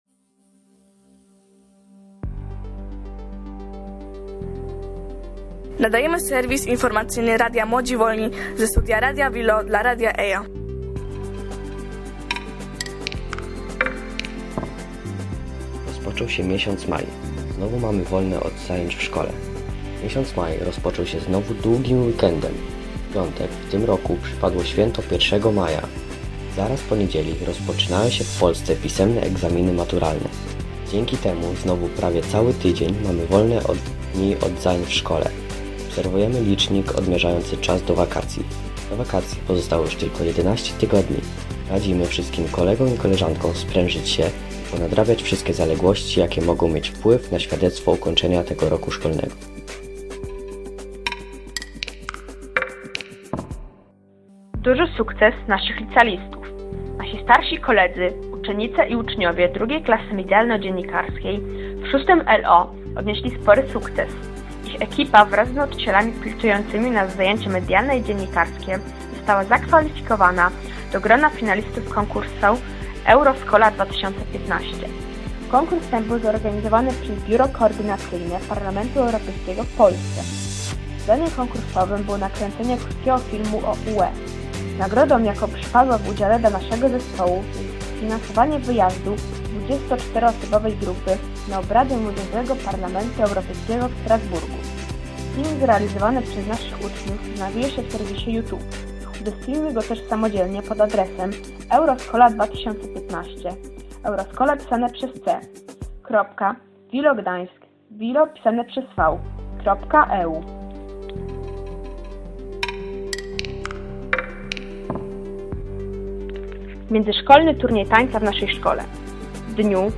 Serwis informacyjny z pierwszych dni maja 2015 dla Radia EYIA oraz Radia VILO